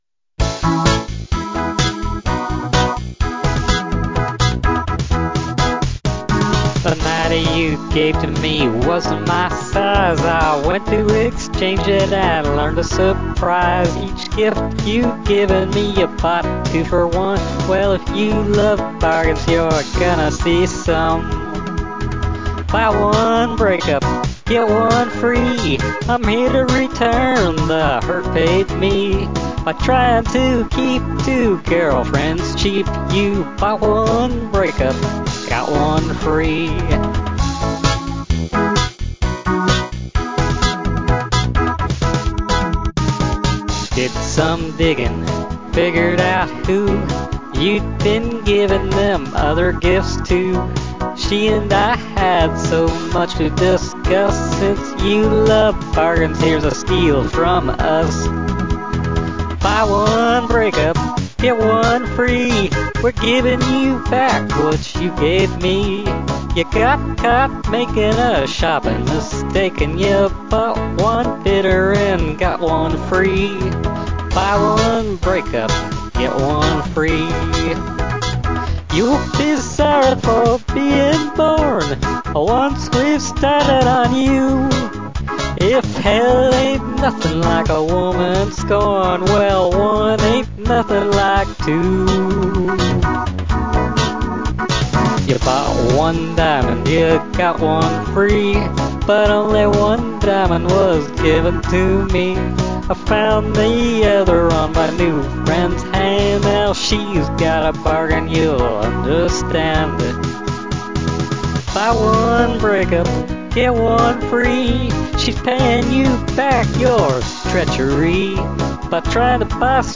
uptempo country, female voice